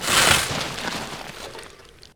wetshovel.ogg